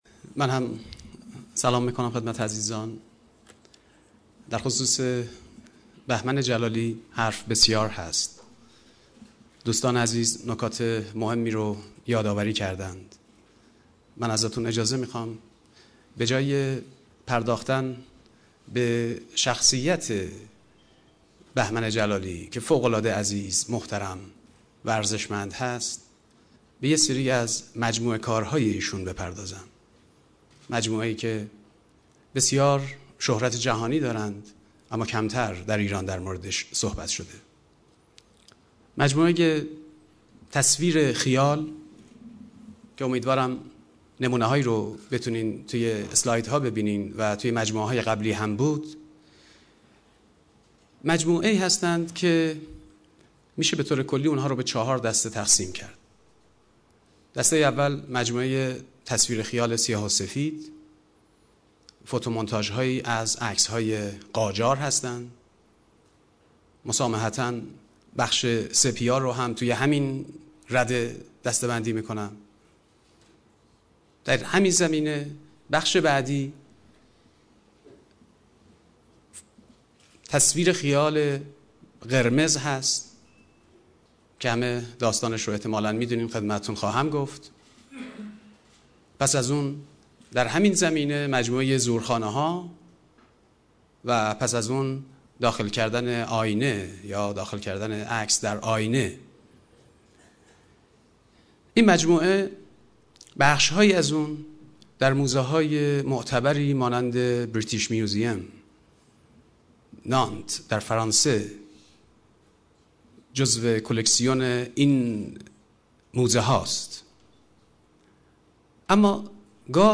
سخنان
در مراسم یادبود بهمن جلالی